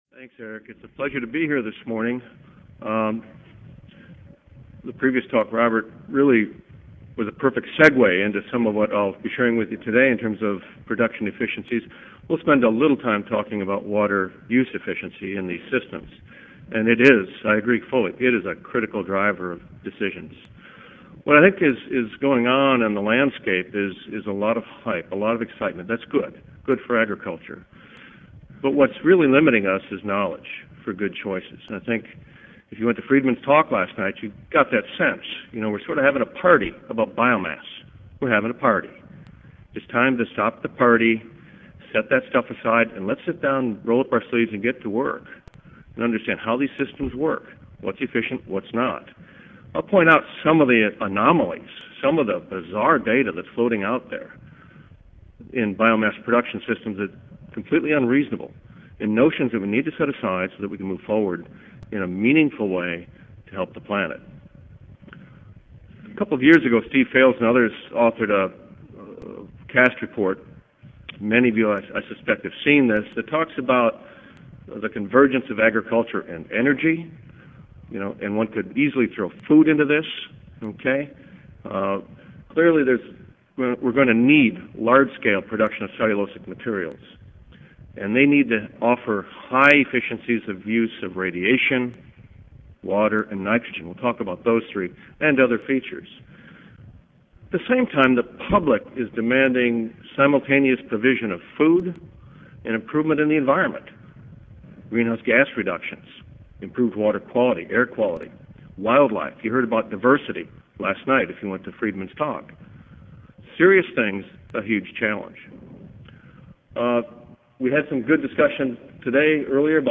Purdue University Audio File Recorded presentation